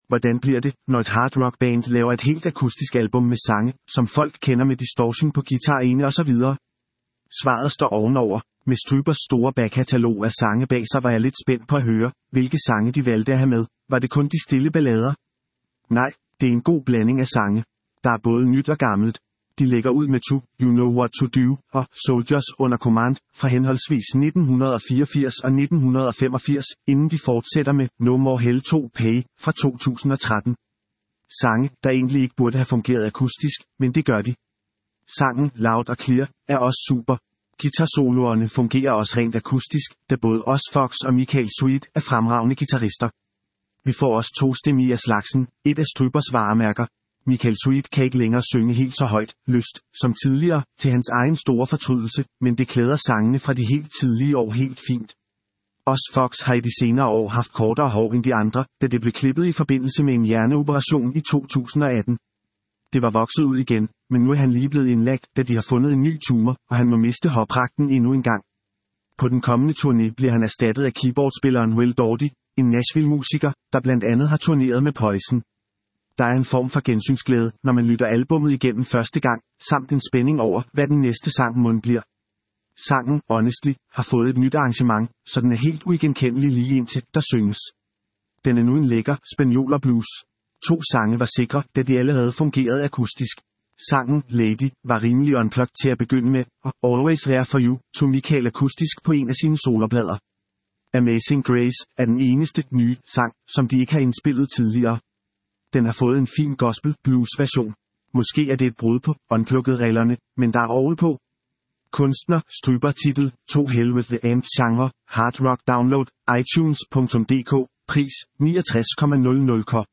Genre: Hard Rock